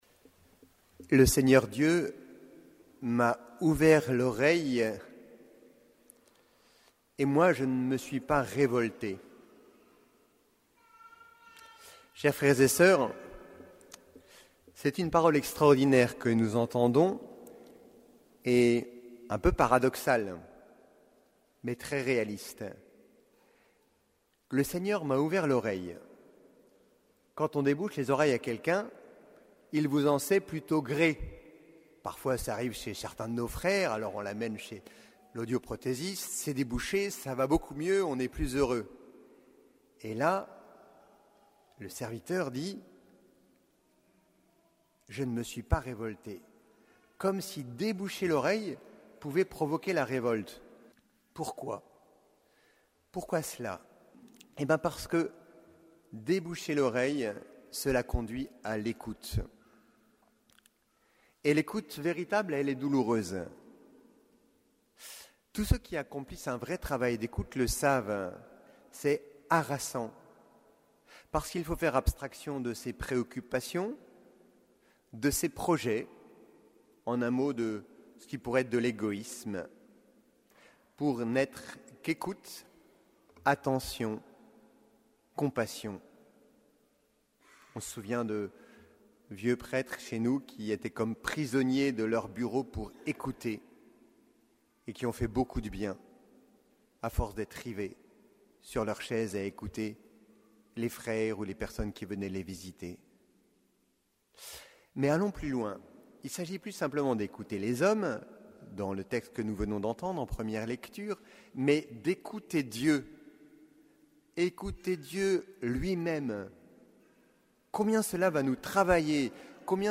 Homélie du 24e dimanche du Temps Ordinaire